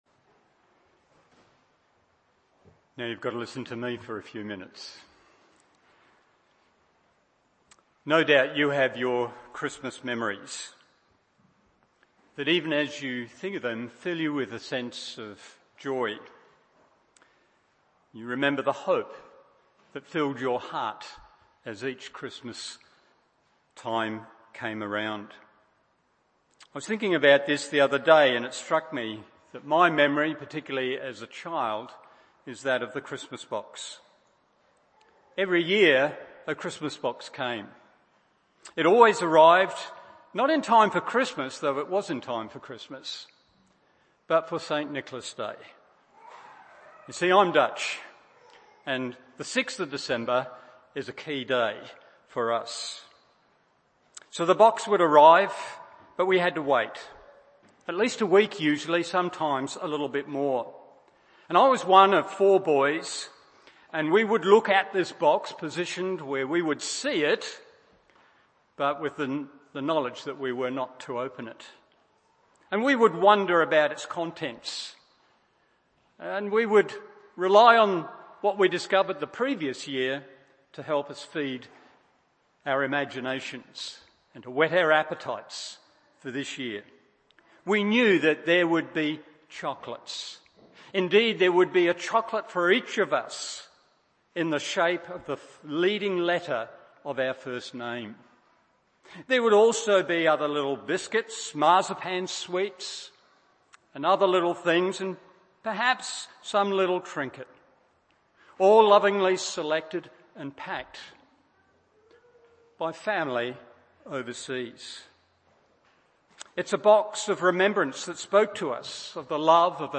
Carol Service
Christmas Carol Service…